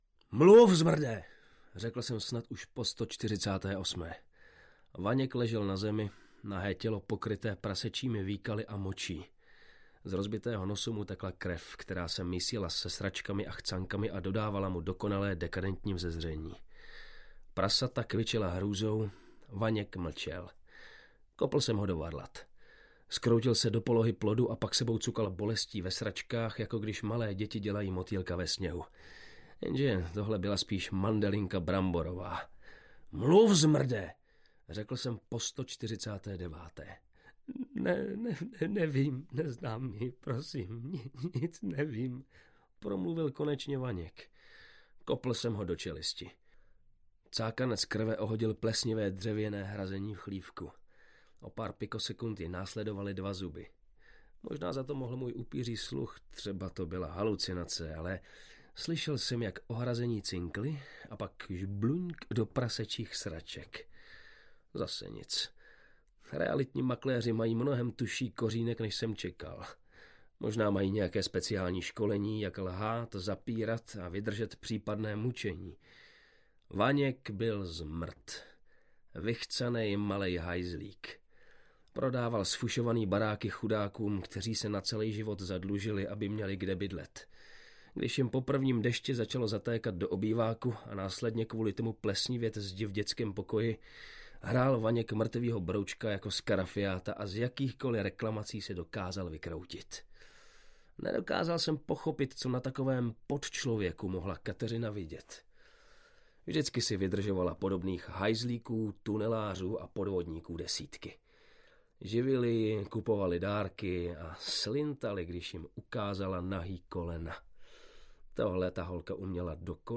Hustej nářez audiokniha
Ukázka z knihy